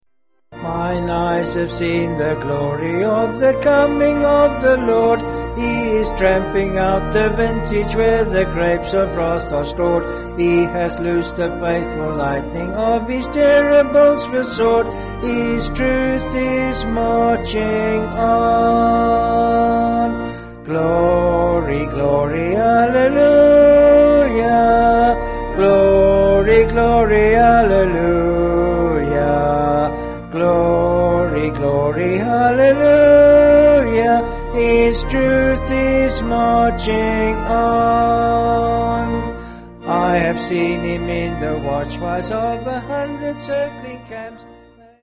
Vocals and Band   261.3kb Sung Lyrics